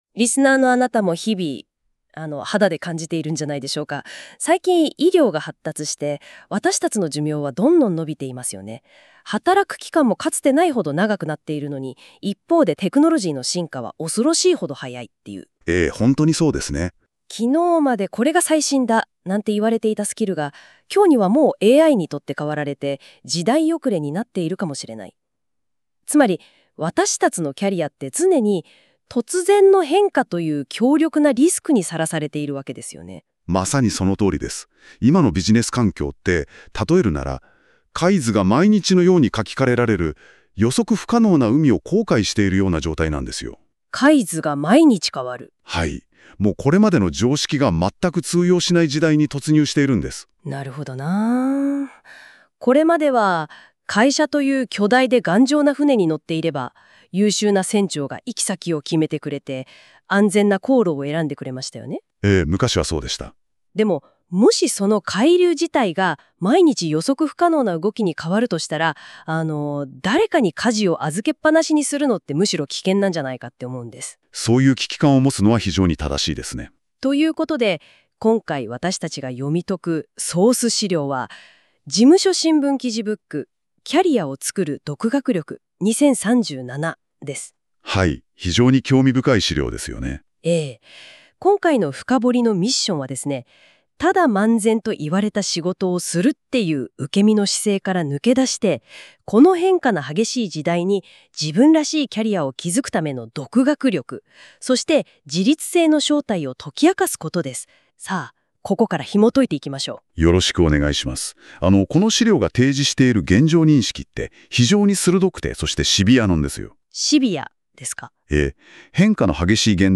この記事の解説を対話形式の音声ファイル（mp4）で再生（18分） ↓ 医療技術の進歩や健康情報の普及により長寿化がもたらされ、職業人生も長くなる一方だ。